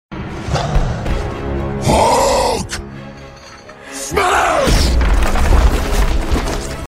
Звуки Халка
Скачивайте или слушайте онлайн его легендарный рык, яростные крики, разрушительные удары и угрожающее дыхание.